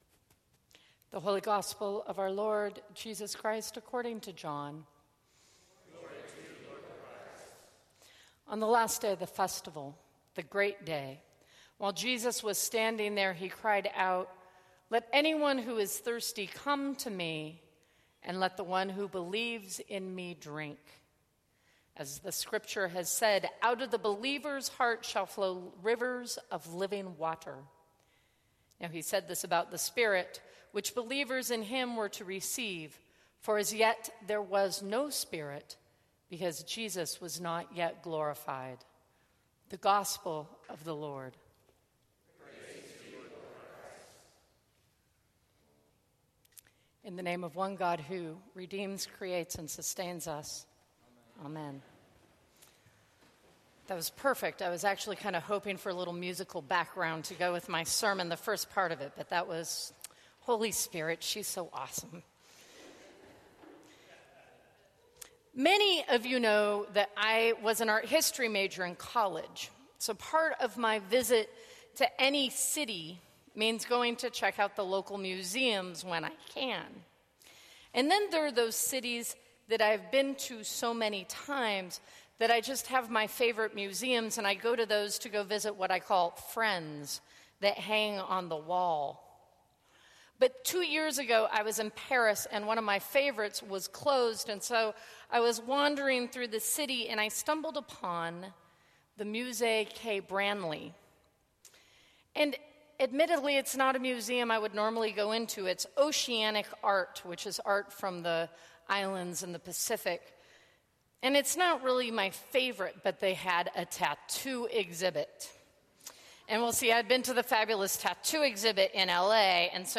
Sermons from St. Cross Episcopal Church Living Water Oct 16 2015 | 00:11:26 1x 00:00 / 00:11:26 Subscribe Share Apple Podcasts Spotify Overcast RSS Feed Share Link Embed